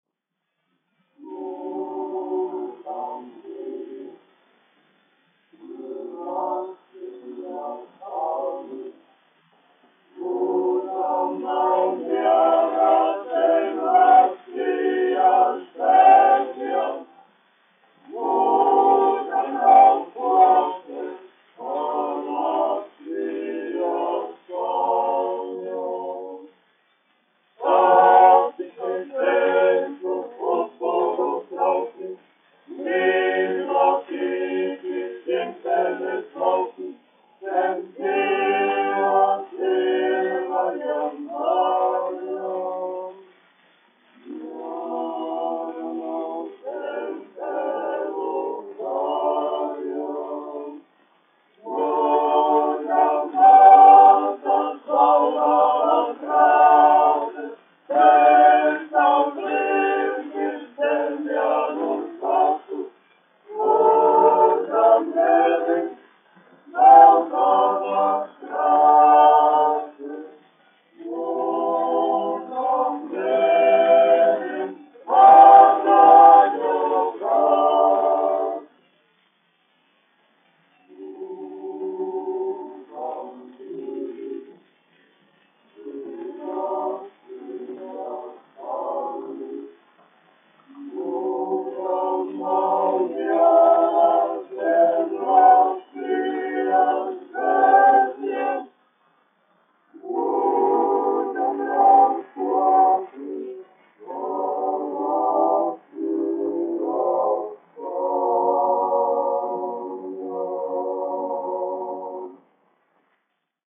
Rīgas Latviešu dziedāšanas biedrības jauktais koris, izpildītājs
Jozuus, Pēteris Pauls, 1873-1937, diriģents
1 skpl. : analogs, 78 apgr/min, mono ; 25 cm
Kori (vīru)
Skaņuplate